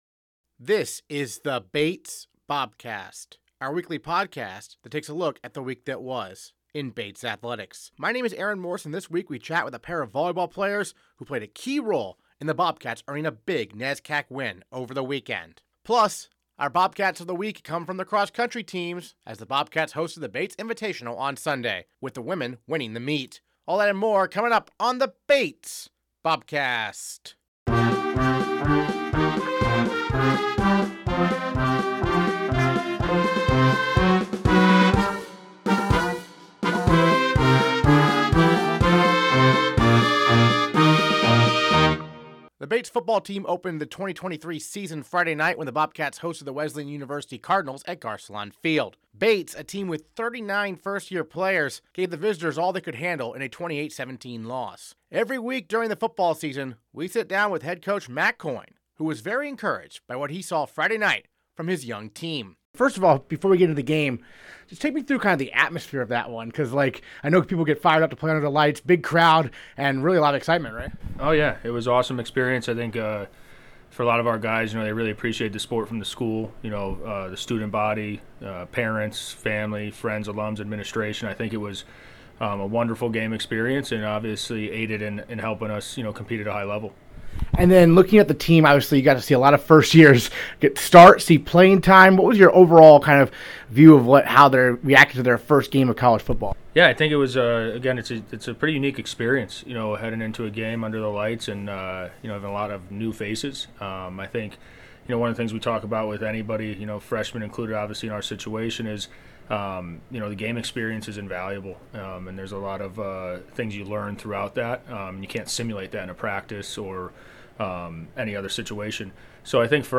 This week on the Bobcast we chat with a pair of volleyball players who played a key role in the Bobcats earning a big NESCAC win over the weekend. Plus, our Bobcats of the Week come from the cross country teams, as the Bobcats hosted the Bates Invitational on Sunday…with the women winning the meet.